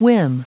Prononciation du mot whim en anglais (fichier audio)
Prononciation du mot : whim